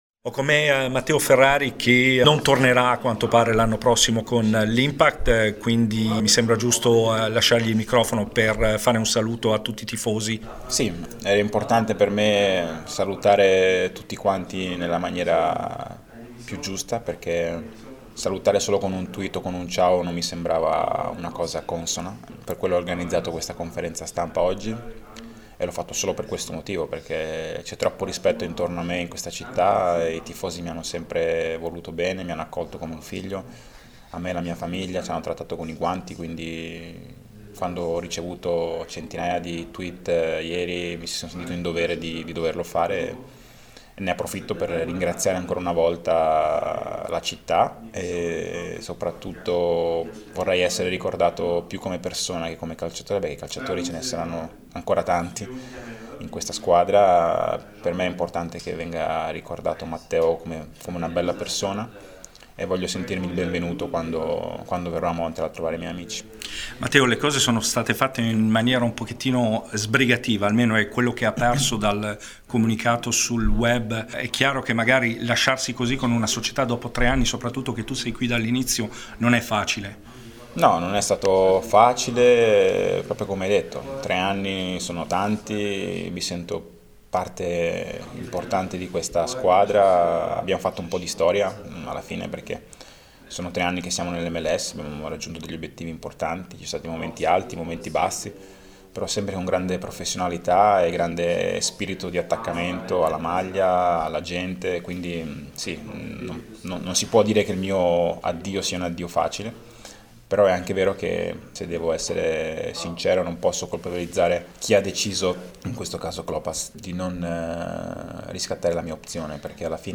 Intervista a Matteo Ferrari all’indomani del mancato rinnovo del suo contratto con l’Impact di Montreal